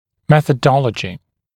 [ˌmeθə’dɔləʤɪ][ˌмэсэ’долэджи]методология